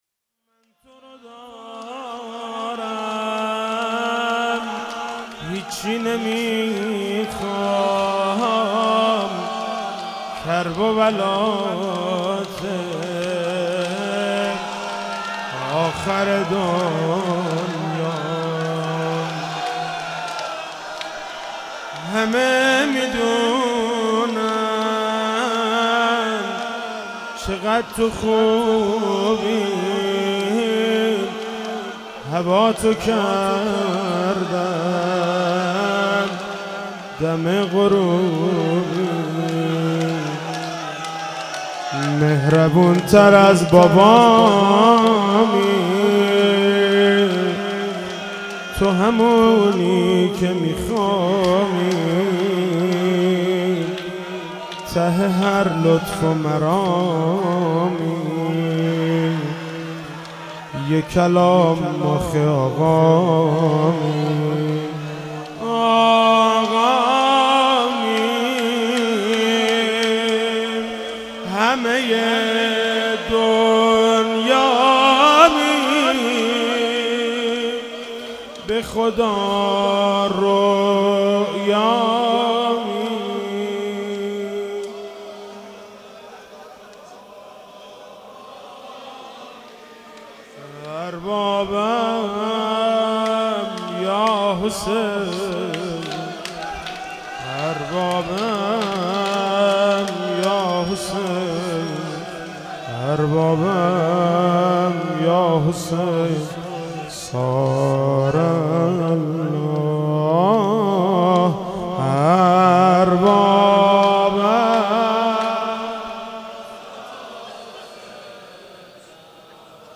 روضه - من تورو دارم هیچی نمیخوام